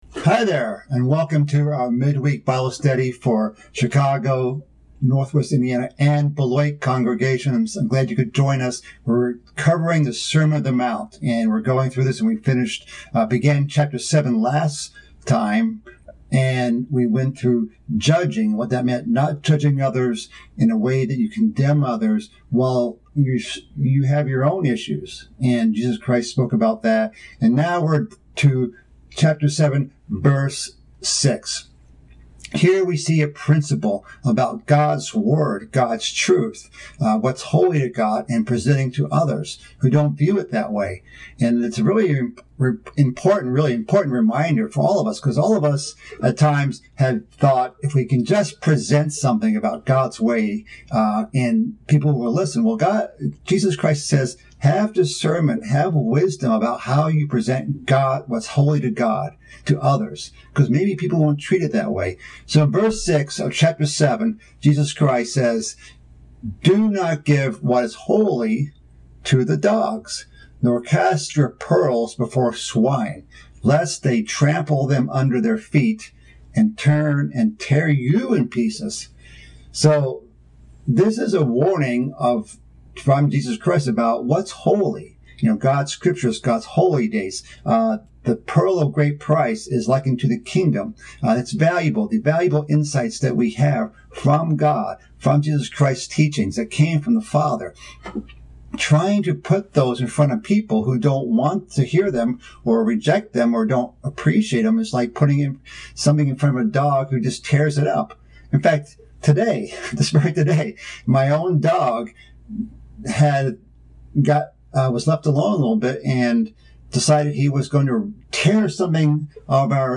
This is part of a mid-week Bible study series about the sermon on the mount. This section of the sermon on the mount urges us to ask, seek, and knock. The study wraps up by touching on the "golden rule."